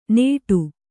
♪ nēṭu